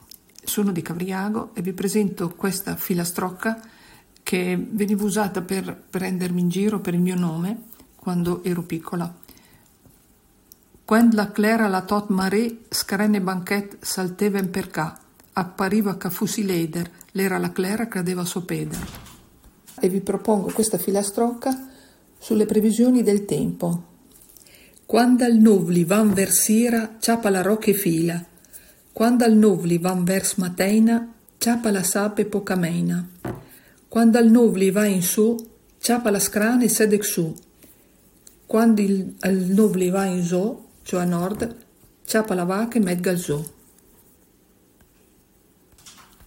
Recita due brevi filastrocche, una relativa al suo nome e l’altra legata alle previsioni del tempo che si facevano in passato.